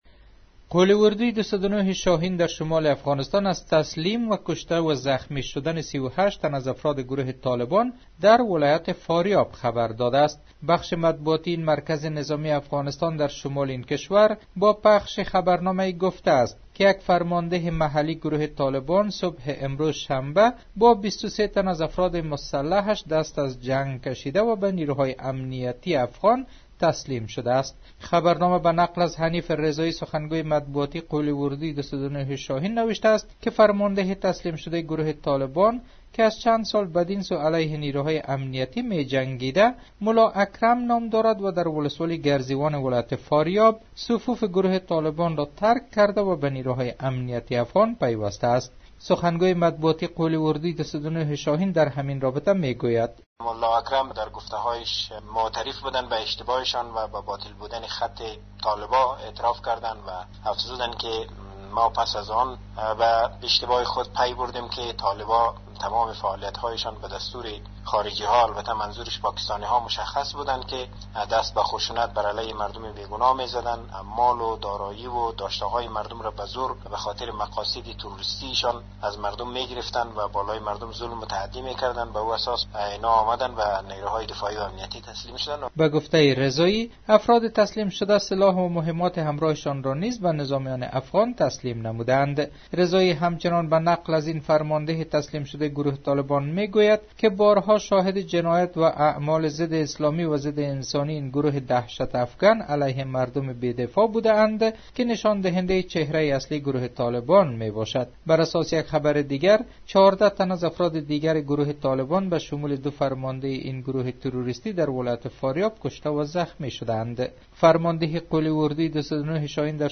خبر رادیو